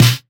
LSNARE 1.wav